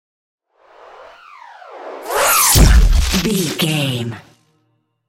Whoosh to hit sci fi disappear debris
Sound Effects
Atonal
dark
futuristic
tension
woosh to hit